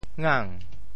潮州府城POJ ngáng 国际音标 [ŋan]
ngang2.mp3